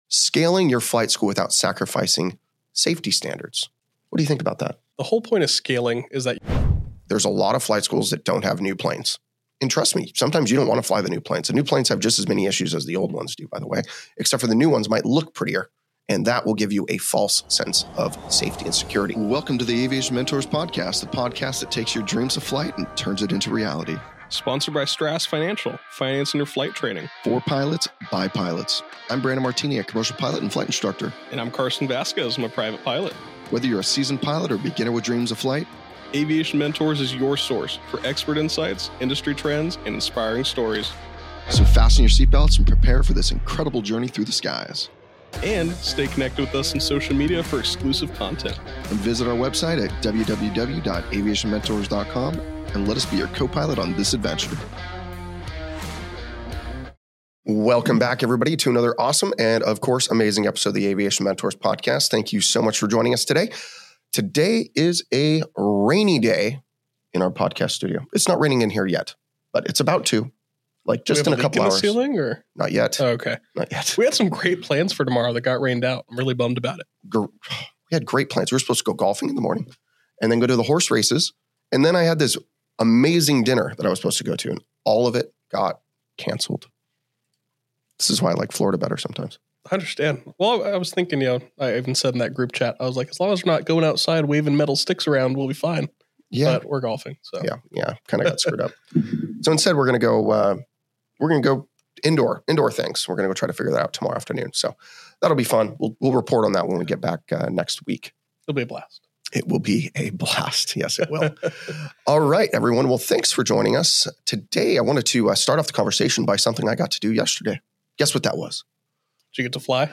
And we're at IGN Live!